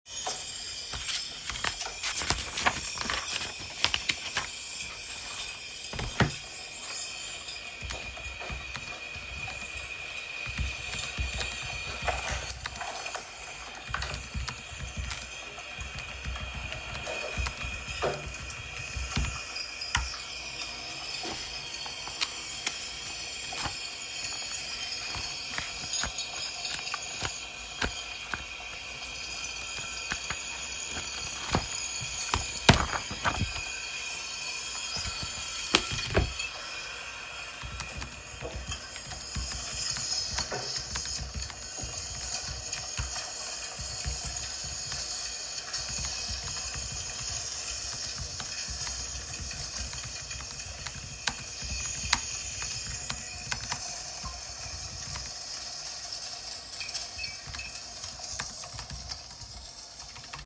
The exercise was structured as a collective sound experiment: each participant recorded their immediate soundscape—including ambient noise, body sounds, and soft, intentional utterances—while listening to the previous participant’s recording. The process created an evolving chain of echoing, overlapping, distorted audio layers. The result was a soundscape that was at once intimate, uncanny, and disorienting.
Audio of the Sleepwalker sound experiment